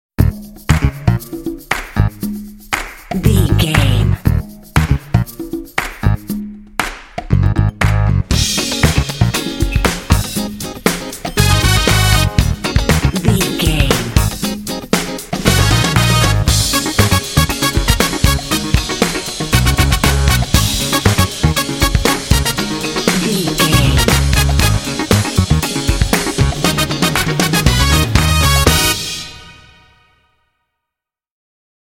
Epic / Action
Aeolian/Minor
E♭
groovy
energetic
driving
bouncy
bass guitar
saxophone
brass
piano
drums